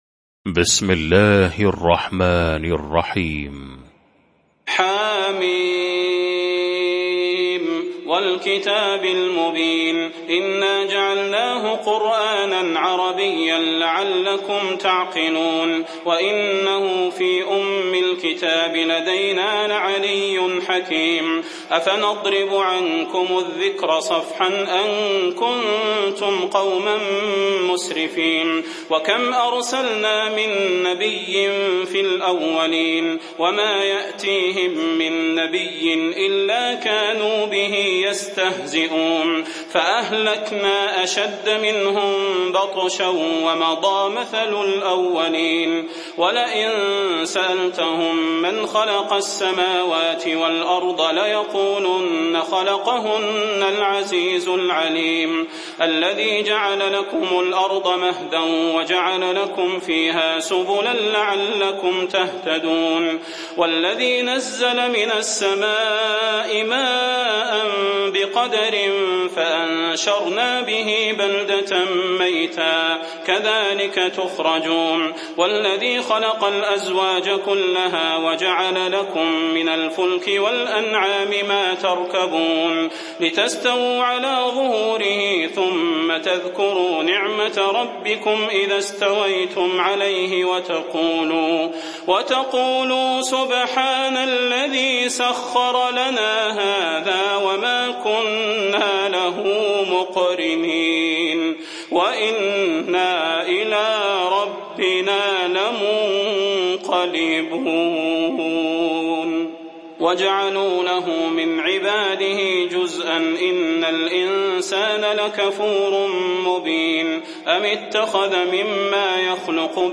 المكان: المسجد النبوي الشيخ: فضيلة الشيخ د. صلاح بن محمد البدير فضيلة الشيخ د. صلاح بن محمد البدير الزخرف